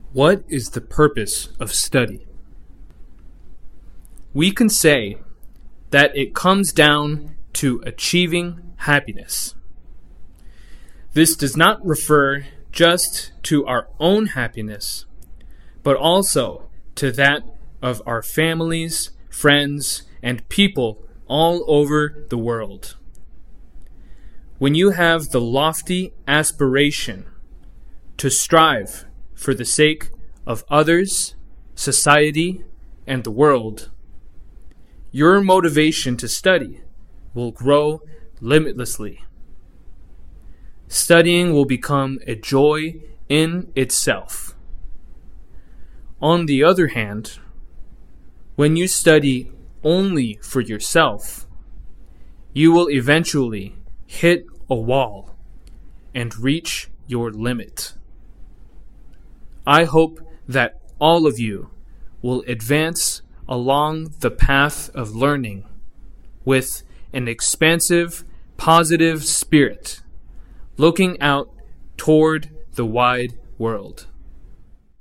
模範音声再生（スロー版） 模範音声再生（スロー版）
c_slow_english2025_model.mp3